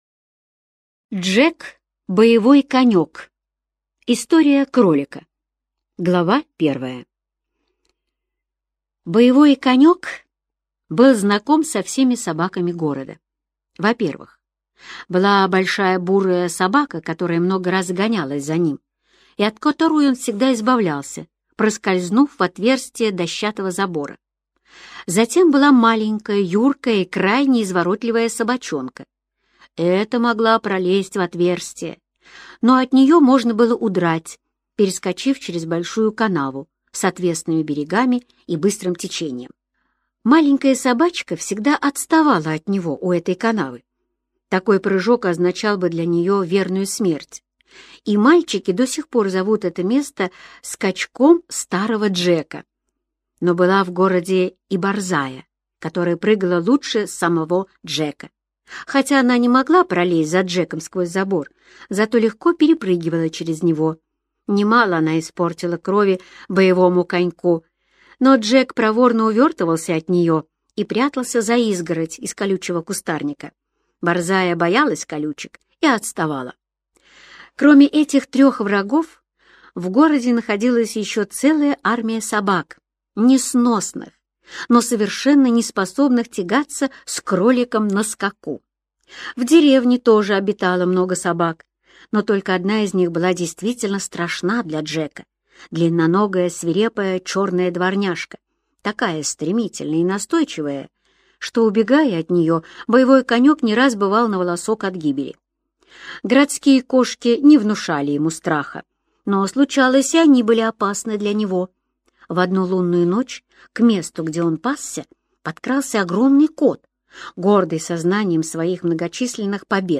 Джек - боевой конек - аудио рассказ Эрнеста Сетона-Томпсона - слушать скачать